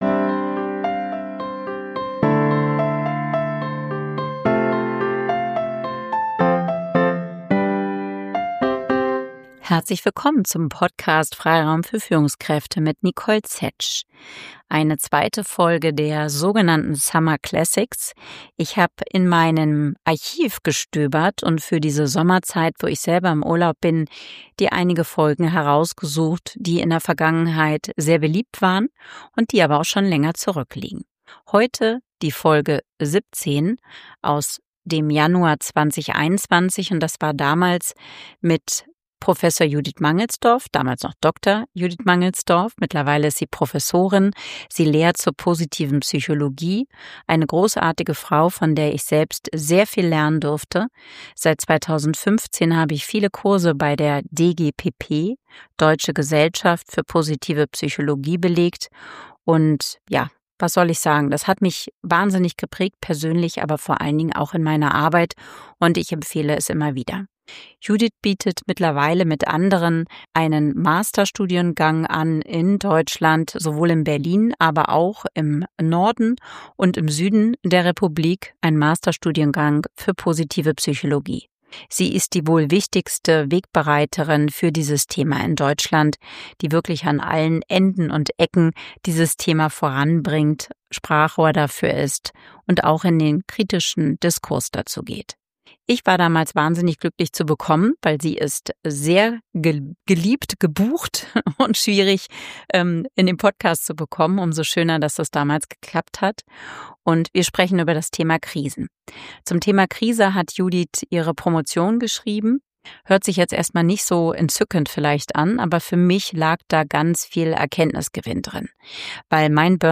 Gemeinsam haben wir darauf geschaut, was es braucht, um aus Krisen zu wachsen. Ein starkes Gespräch mit Tiefgang und Weisheit.